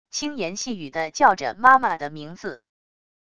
轻言细语的叫着妈妈的名字wav音频